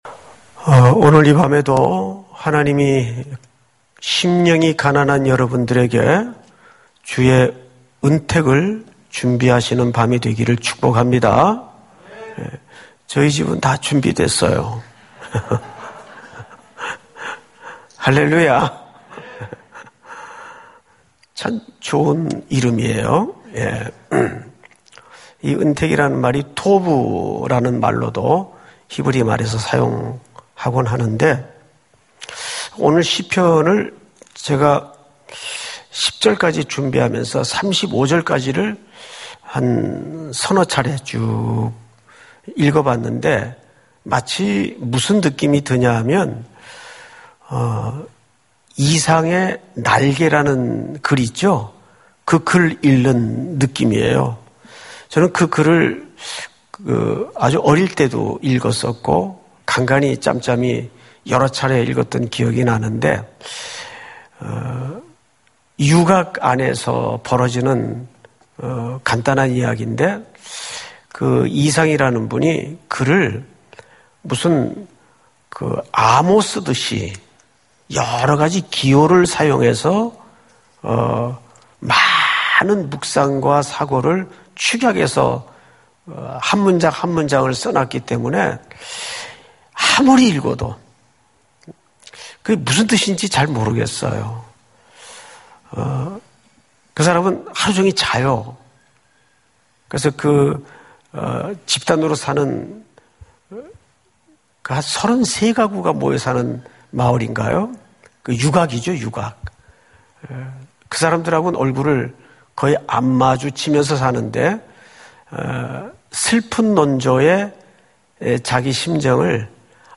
철야예배